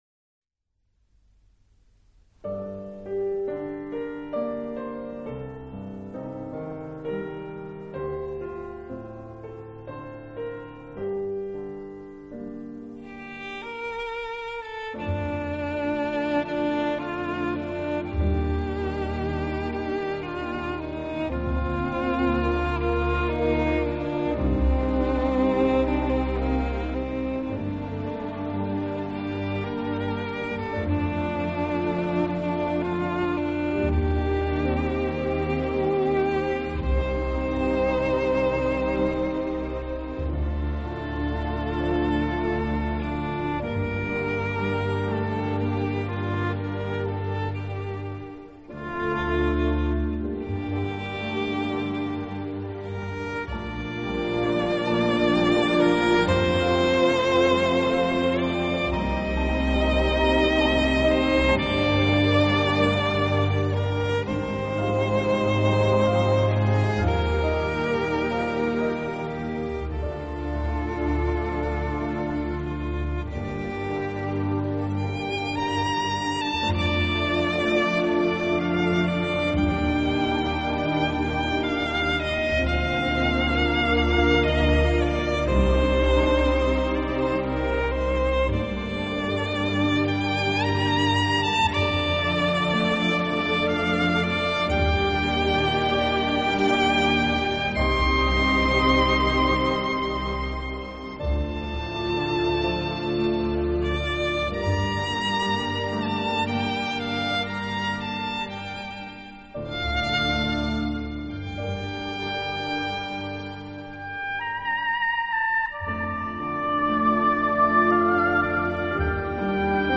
小提琴专辑
类型：Class
他和自己的小型管弦乐队默契配合，将古典音乐与大众音乐进行了有机的融合，